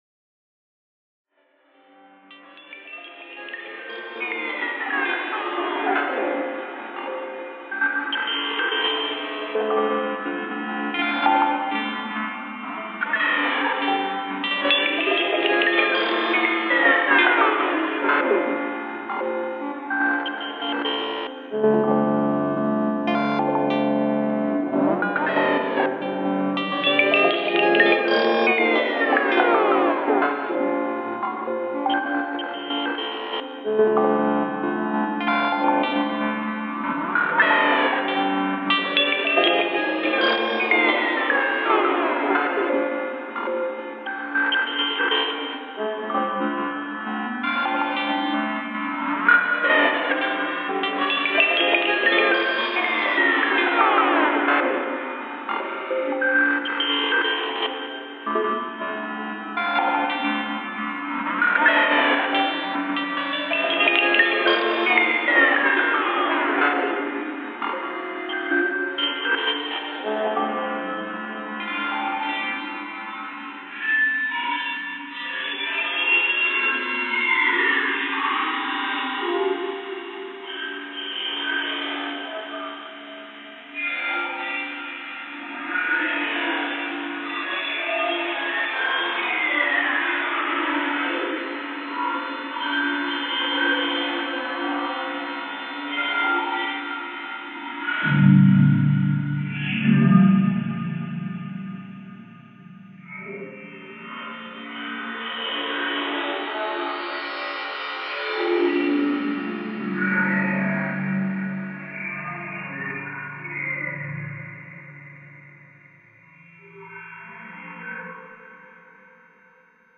Intentions: making odd loops to feed into Octatrack!
This is from 5 minutes of randomly pressing stuff on Loupé :laughing: